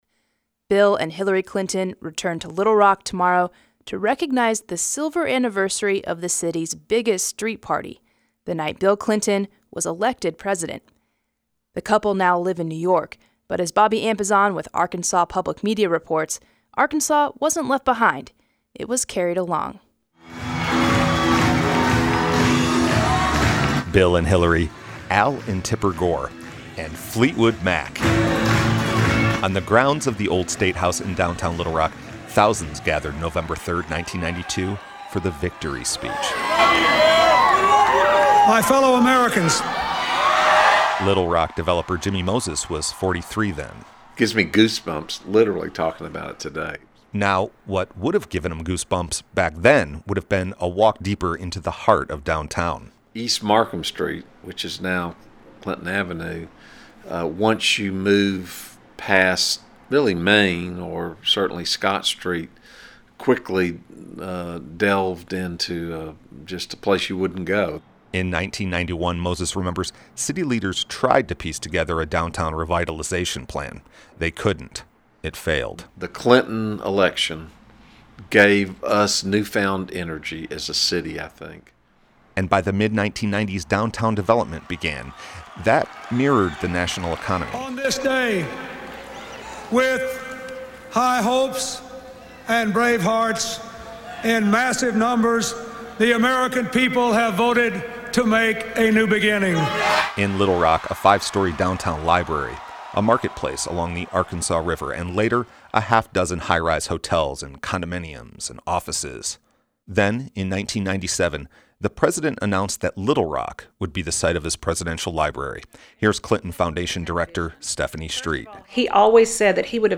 Sound stories
“When you talk about a real disjoiner in terms of the Clinton presidency, many of my Republican friends will tell you, on balance, the Clinton presidency was very good for the city and good for the state regardless of your political affiliation,” he said from an airline ticket counter inside the Bill and Hillary National Airport.